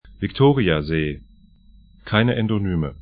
Aussprache
Victoriasee vɪk'to:rĭaze: Sango